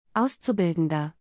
This word is also best pronounced word-by-word, step-by-step.